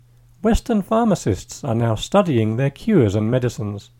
DICTATION 10